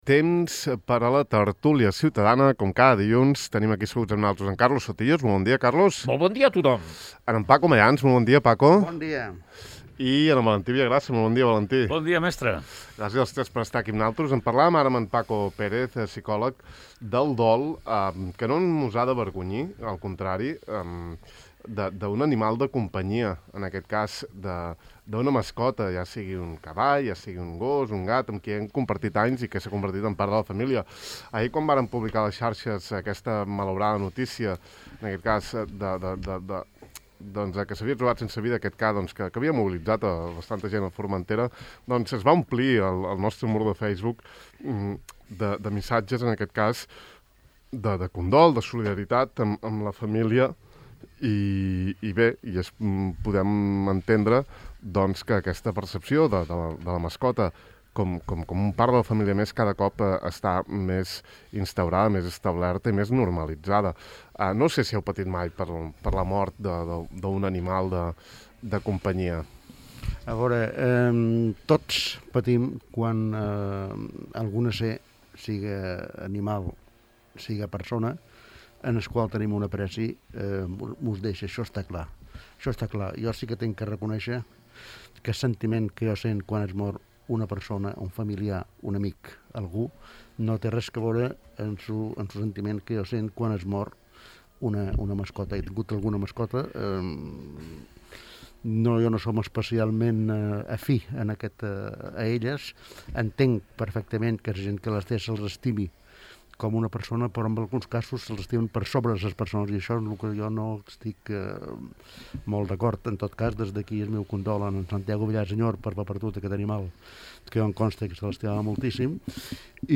Heu vist la galtada? Què en penseu? Ha set un tels temes en la tertúlia ciutadana d'avui
Ha set un dels temes que han discutit els nostres tertulians d'avui.